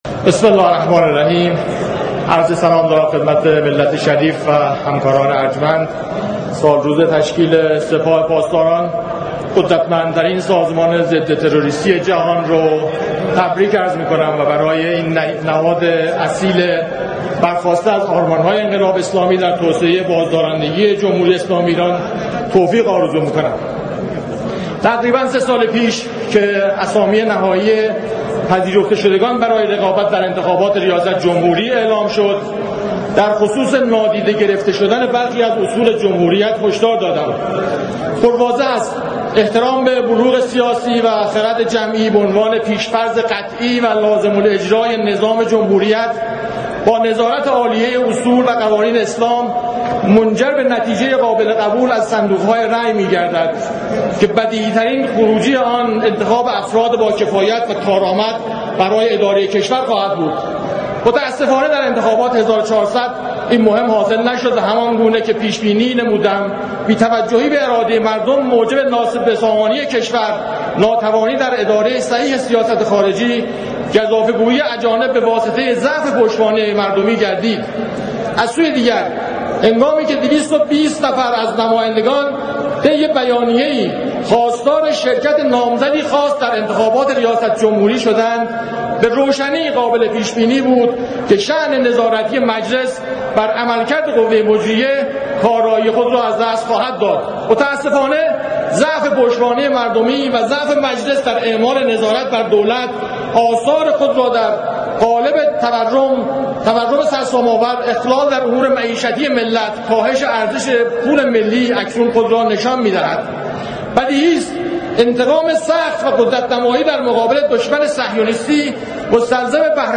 نطق طوفانی دکتر علیرضابیگی در جلسه علنی امروز مجلس/ در مقابل گردنکشان و بدخواهان ملت ایران خواهم ایستاد+صوت